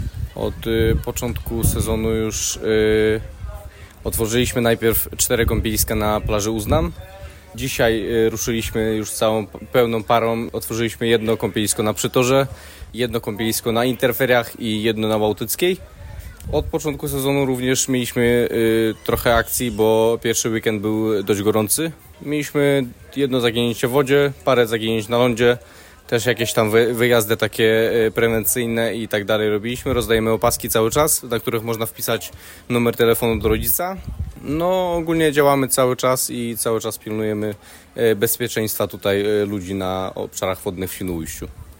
Świnoujscy ratownicy wyjaśniają.